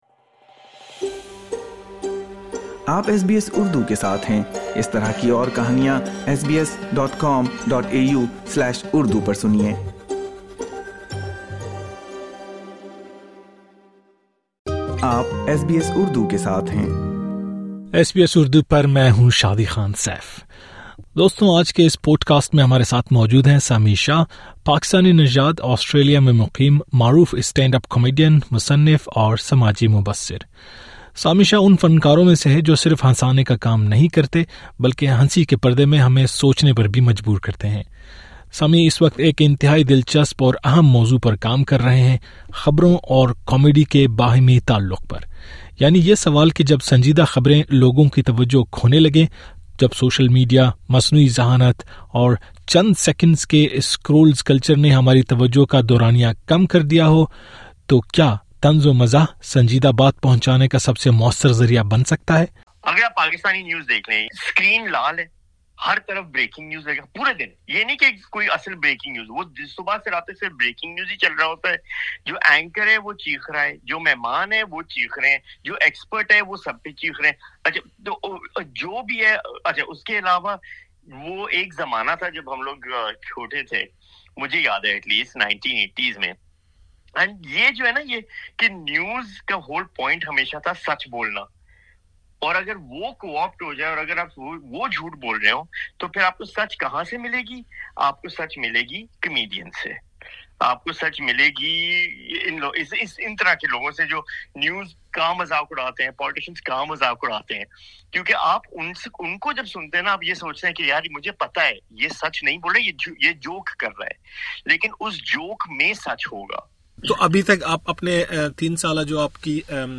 آج کے اس پوڈکاسٹ میں ہمارے ساتھ موجود ہیں سمیع شاہ— پاکستانی نژاد، آسٹریلیا میں مقیم معروف اسٹینڈ اپ کامیڈین، مصنف اور سماجی مبصر۔
Sami Shah is Pakistan-origin stand up comedian Credit: sami shah